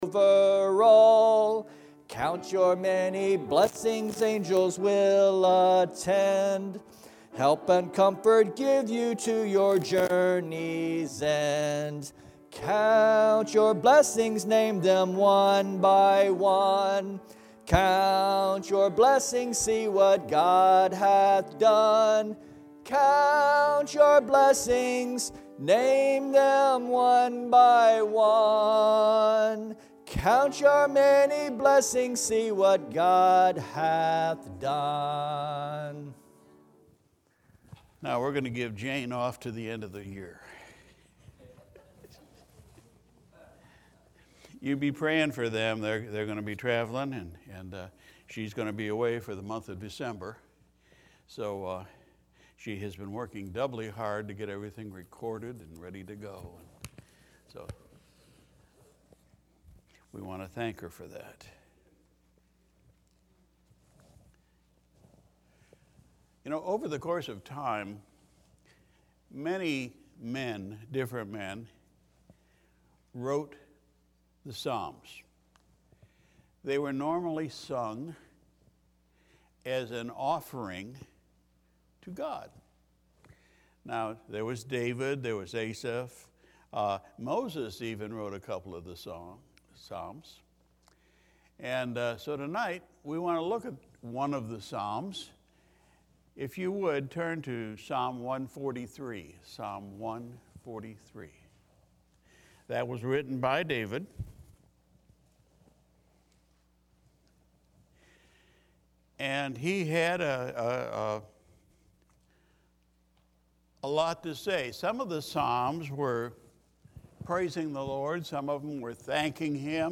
November 29, 2020 Sunday Evening Service Title: “Psalm 143”